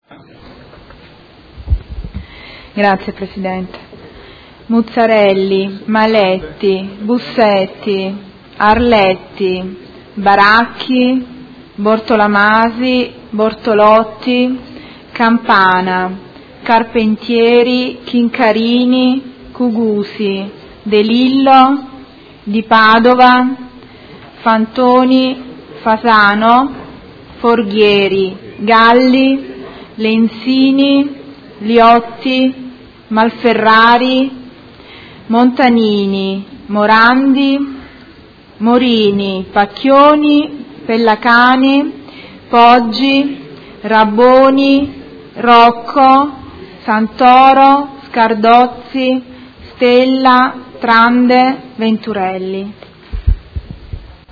Segretario Generale — Sito Audio Consiglio Comunale
Seduta del 1/12/2016 Appello